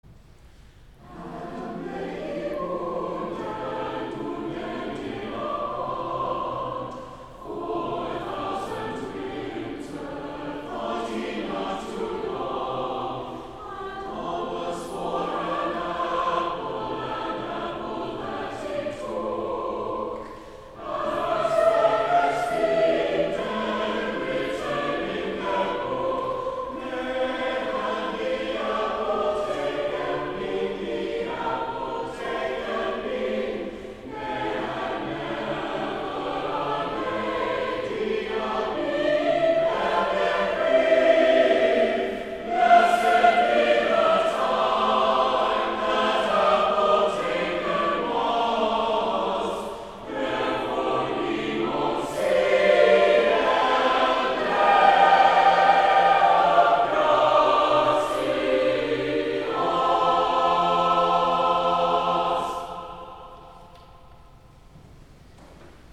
Festival of Nine Lessons and Carols
Cathedral Choir
Carol - Malcolm Archer - Adam lay ybounden